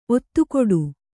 ♪ ottukoḍu